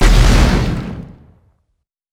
Grenade4Short.wav